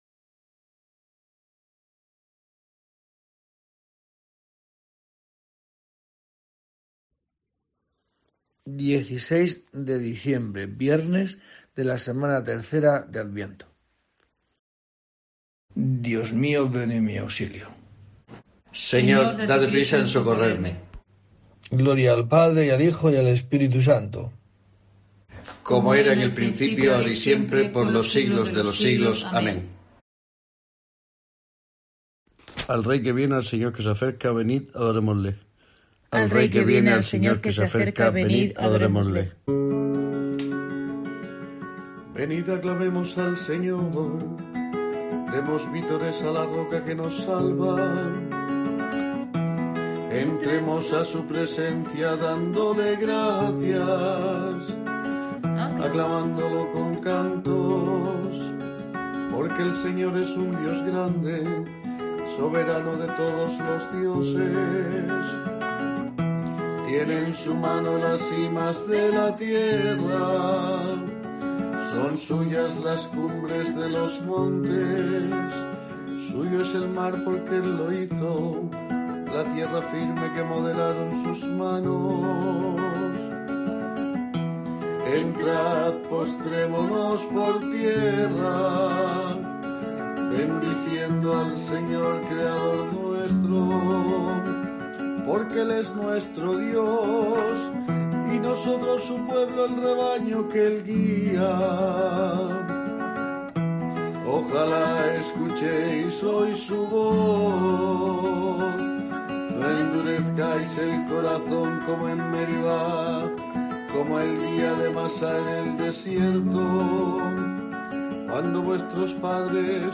16 de diciembre: COPE te trae el rezo diario de los Laudes para acompañarte